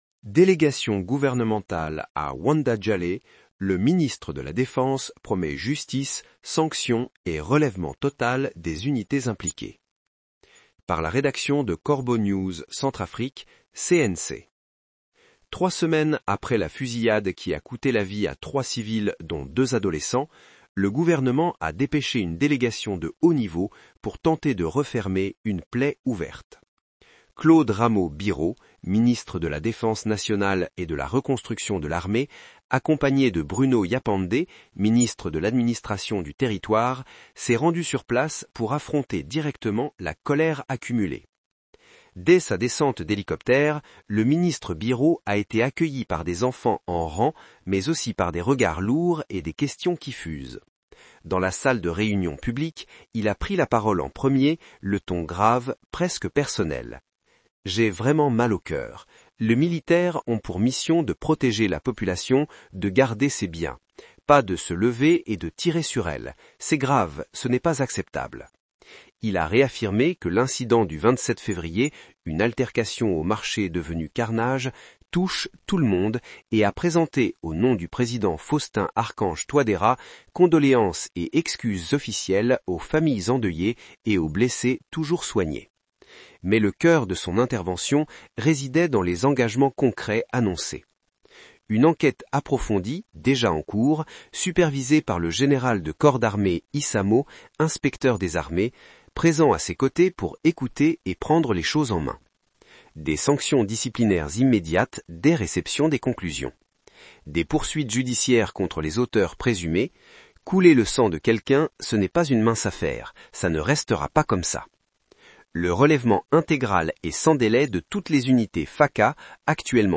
Claude Rameaux Bireau, ministre de la Défense nationale et de la Reconstruction de l’Armée, accompagné de Bruno Yapandé, ministre de l’Administration du territoire, s’est rendu sur place pour affronter directement la colère accumulée.
Dans la salle de réunion publique, il a pris la parole en premier, le ton grave, presque personnel :